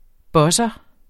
Udtale [ ˈbʌsʌ ]